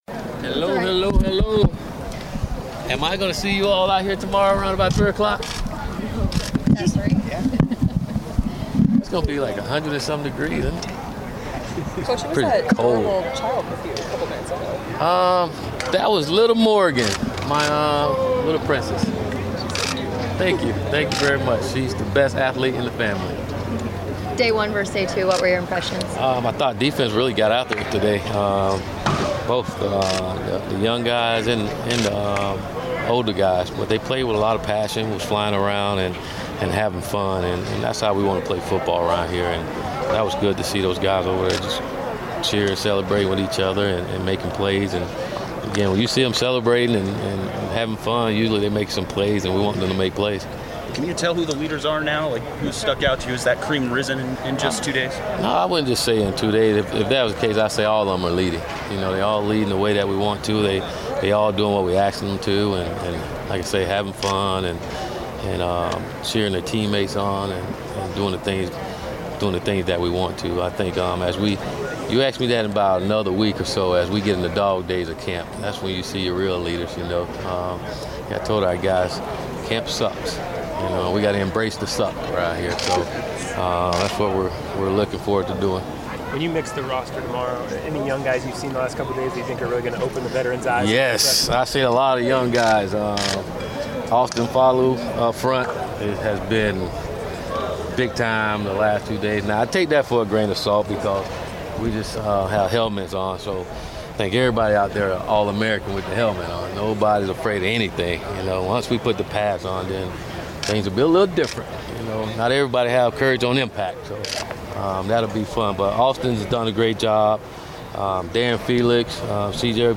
Head coach Willie Taggart speaks with the media after his second fall practice at Oregon.